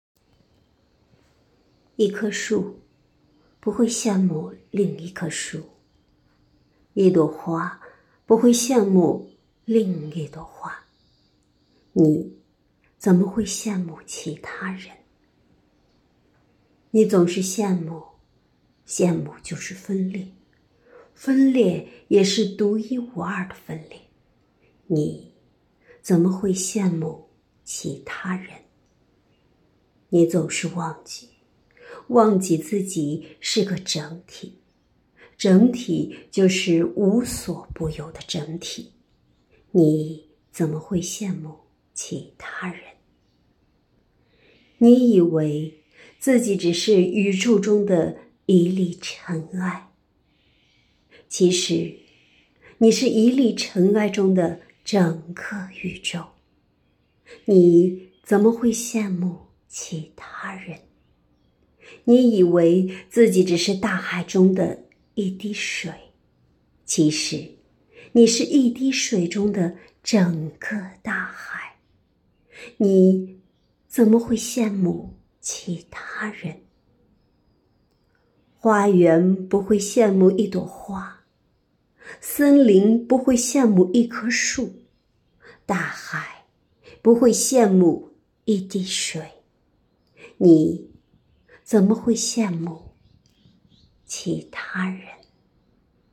诗词朗诵专栏